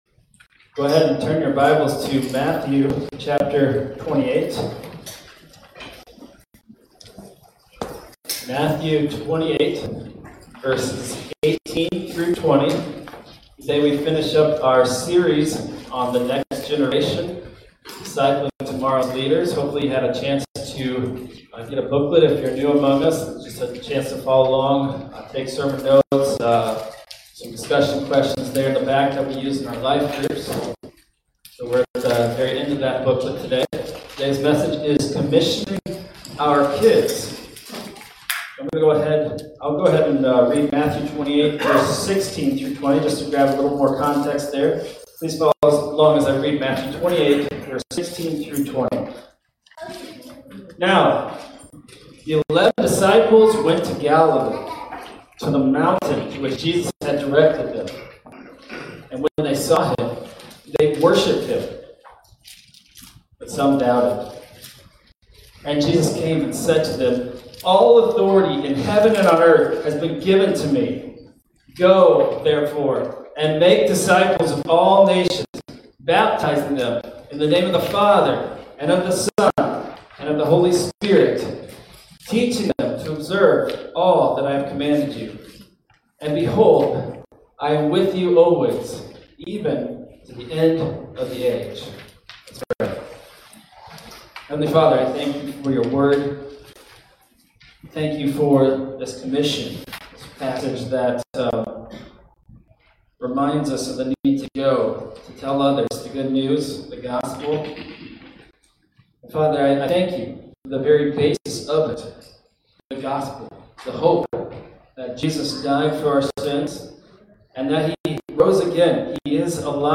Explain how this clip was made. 10 – Commissioning Our Kids – Grace Baptist Church